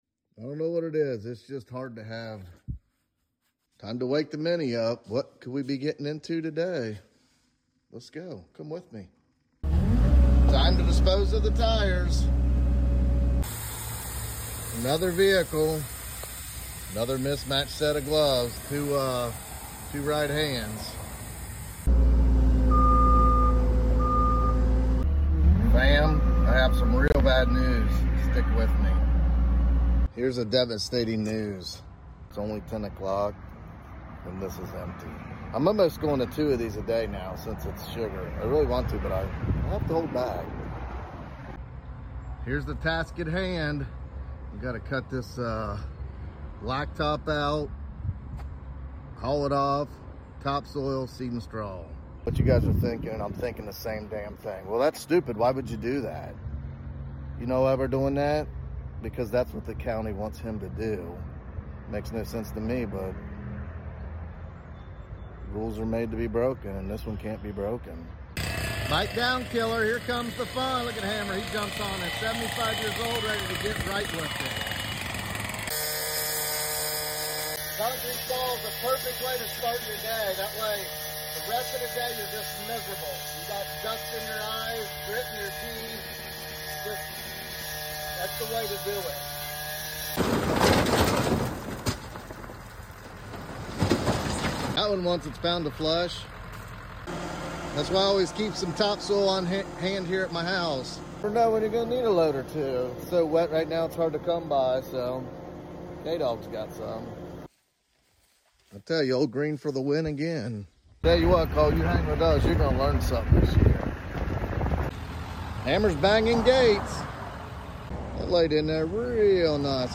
Caterpillar mini Excavator digging up blacktop Excavation.